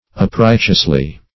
Meaning of uprighteously. uprighteously synonyms, pronunciation, spelling and more from Free Dictionary.
Search Result for " uprighteously" : The Collaborative International Dictionary of English v.0.48: Uprighteously \Up*right"eous*ly\, adv.